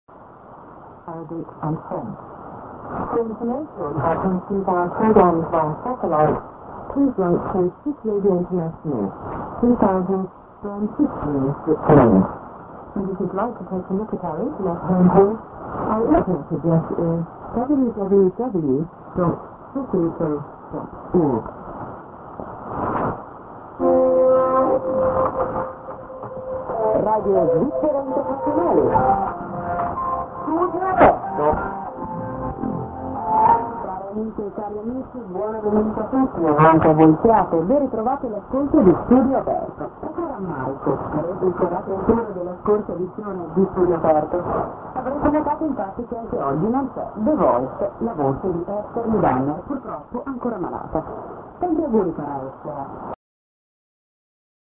St. ID+SKJ(women)->